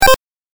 レトロゲーム （105件）
8bitキャンセル1.mp3